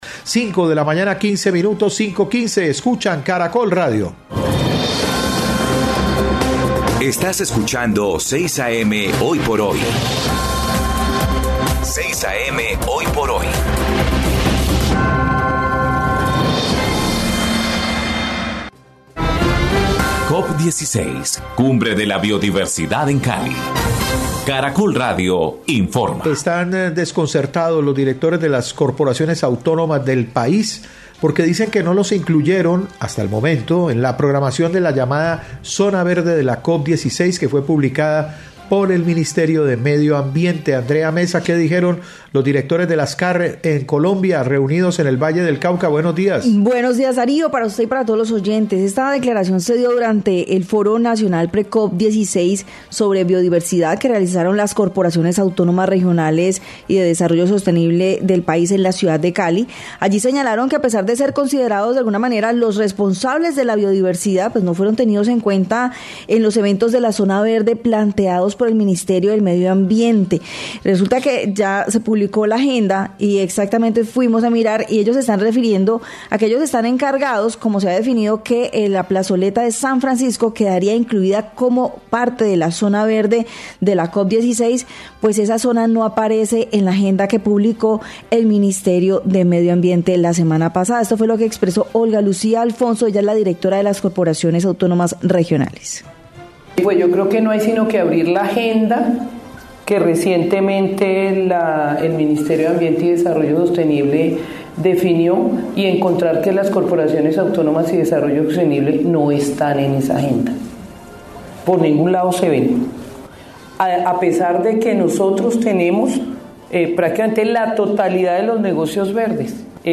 Radio
Entrevista con el director general de la CVC, Marco Antonio Suárez,  denuncia que las Corporaciones Autónomas Regionales-CARs del país no fueron incluidas en la agenda de la COP16 a pesar de ser responsables del medioambiente.